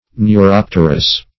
Neuropterous \Neu*rop"ter*ous\, a.